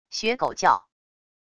学狗叫wav音频